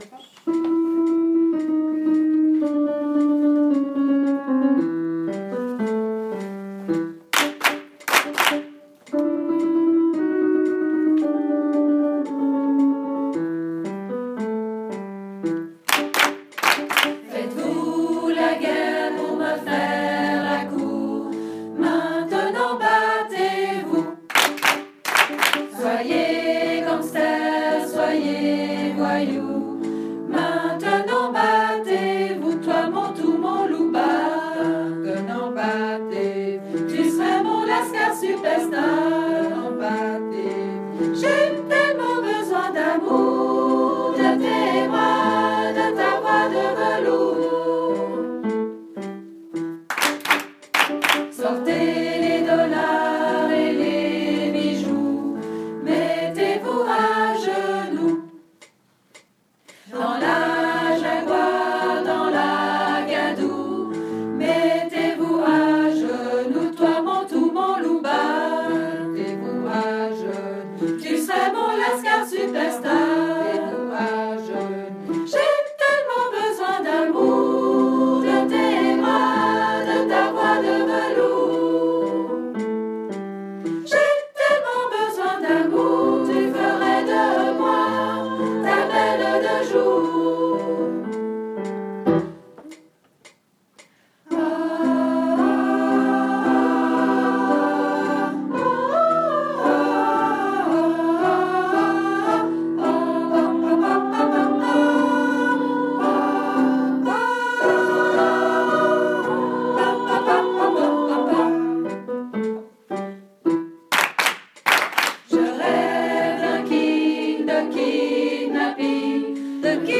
Battez-Vous-Tuttis2.mp3